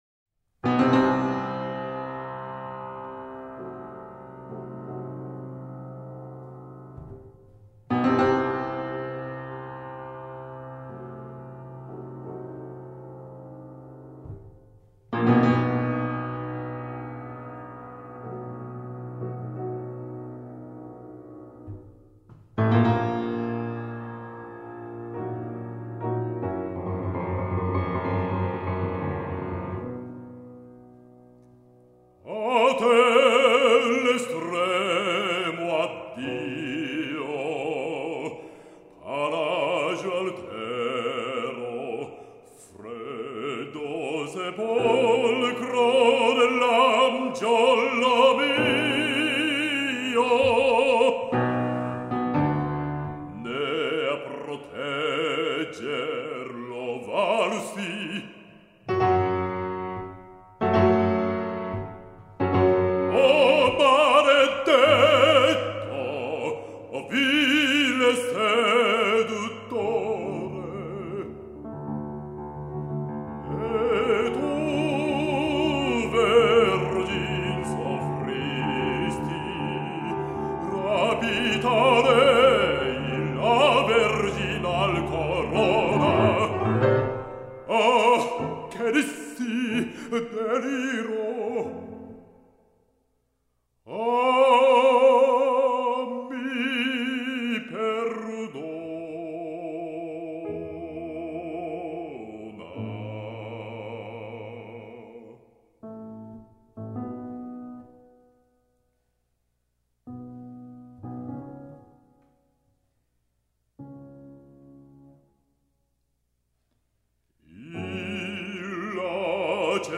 basso
pianista